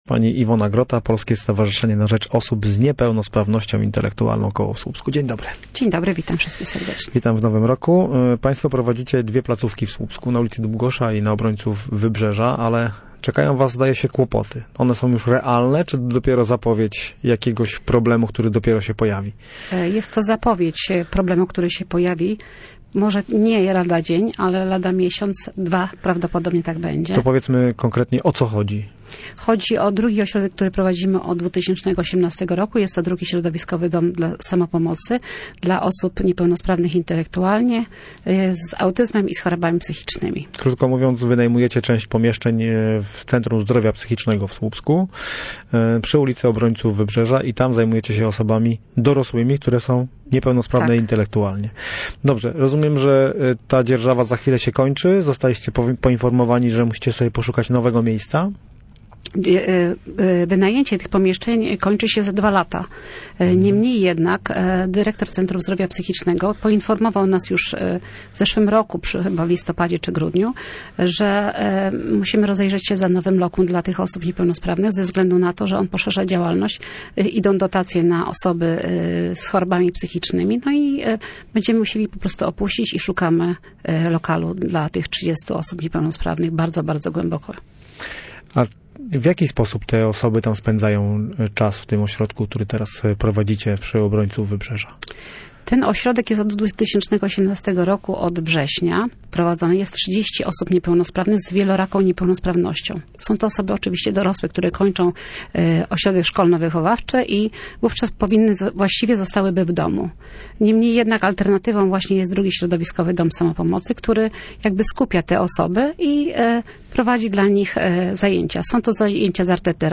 Studio Słupsk 102 FM. Posłuchaj całej rozmowy